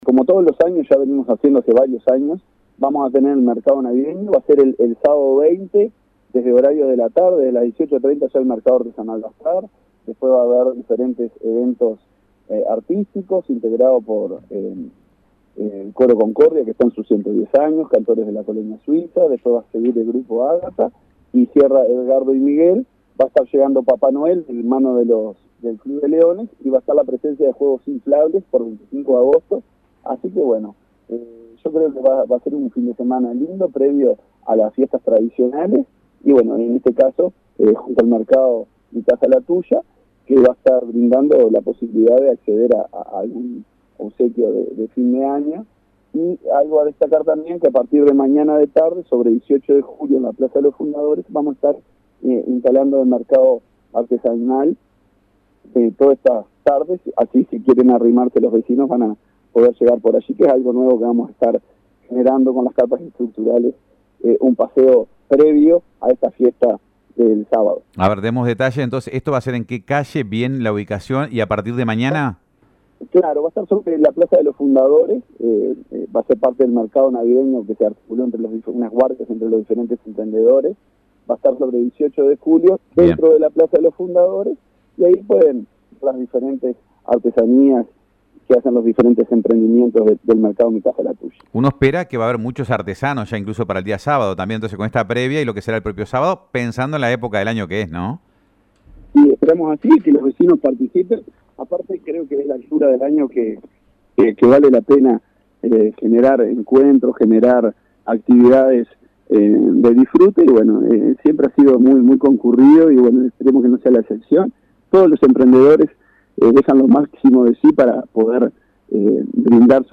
Escuchamos al alcalde de Nueva Helvecia, Marcelo Alonso, quien brindó detalles de la actividad y destacó la importancia de generar espacios de encuentro para la comunidad en estas fechas.